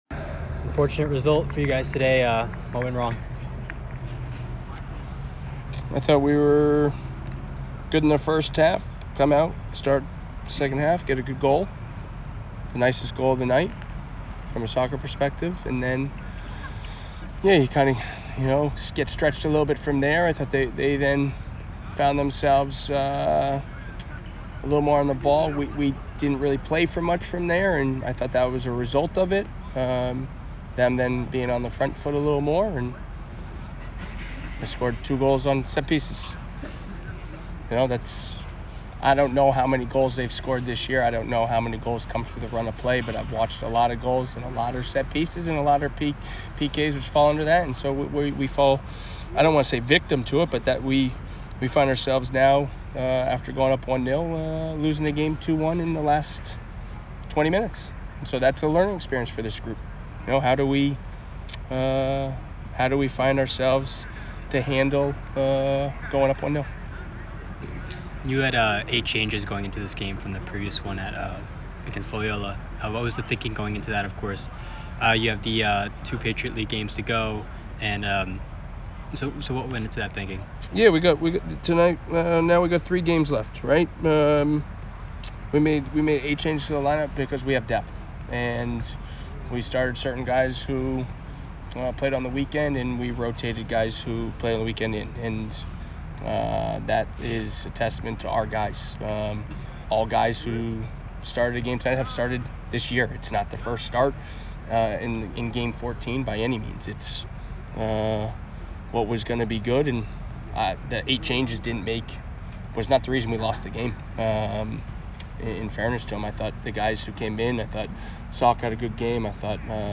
Northeastern Postgame Interview